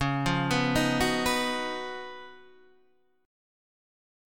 C#11 chord {9 8 9 8 7 7} chord